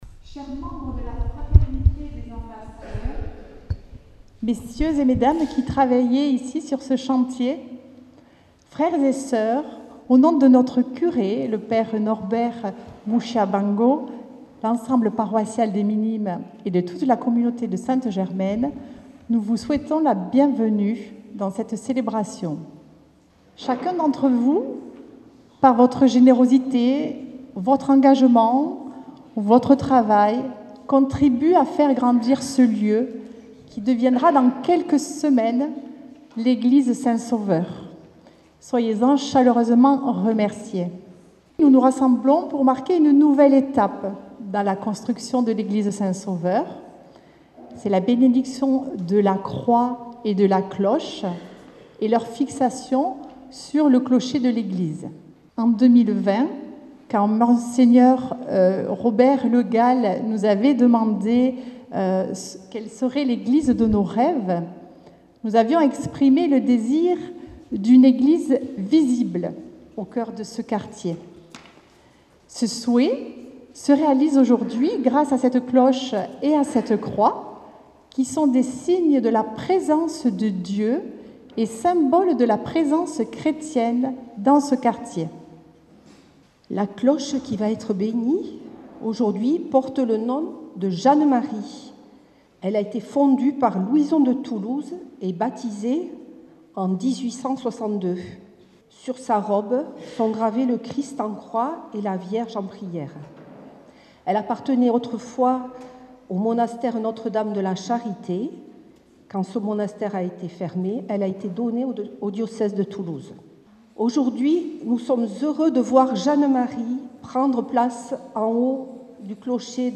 Radio Présence était hier à Toulouse, pour la bénédiction de la croix et de la cloche de la nouvelle église de quartier de Borderouge, l’église Saint-Sauveur.
Un reportage réalisé le mercredi 11 septembre 2024, qui vous fais vivre ou revivre ce moment convivial.